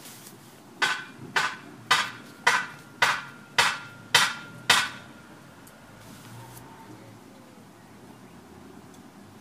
描述：隔壁邻居锤击外面。
Tag: 锤击 花园 建设 施工 外面 施工 建筑